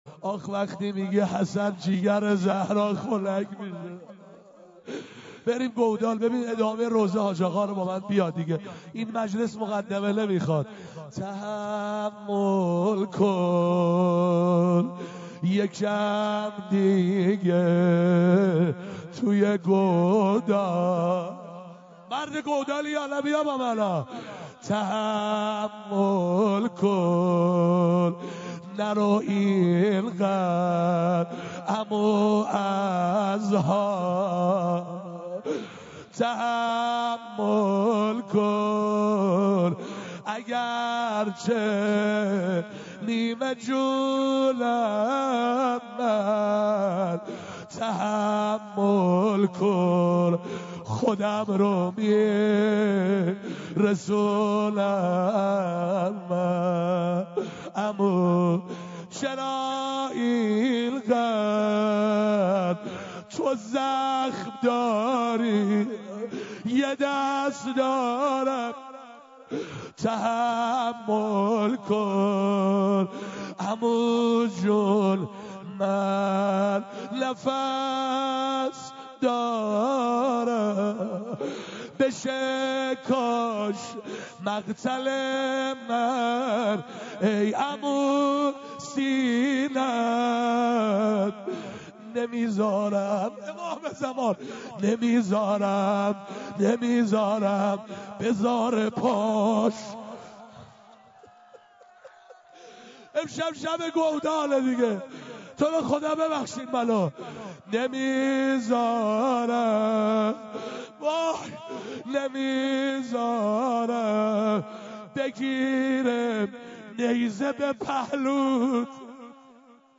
روضه بخش دوم